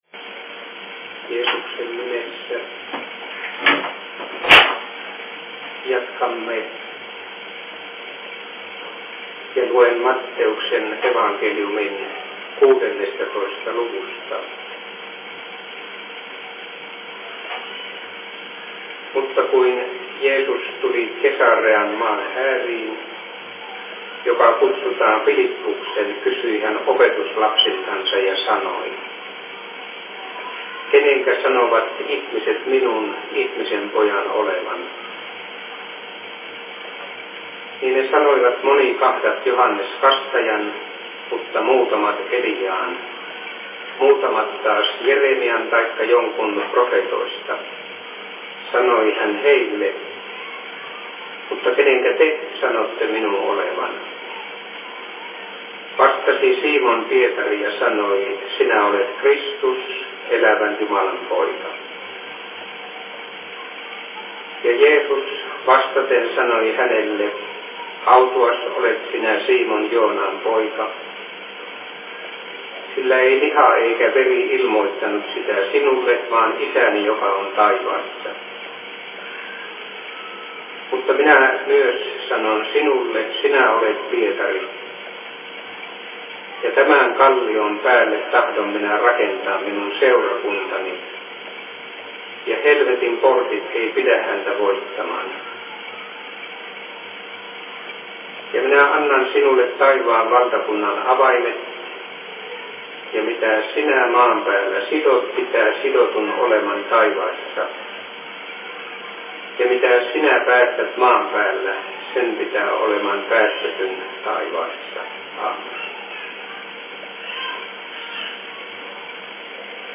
Ranuan maakunnalliset opistoseurat/Seurapuhe 29.07.2013
Paikka: Rauhanyhdistys Ranua